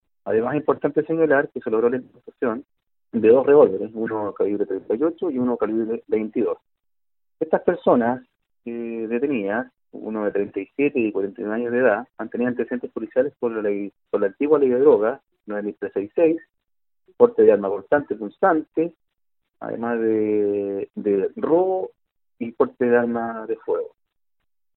Los sujetos cuentan con un amplio prontuario, sostuvo el oficial de la policía civil, ya que habían sido anteriormente detenidos por la comisión de diversos delitos.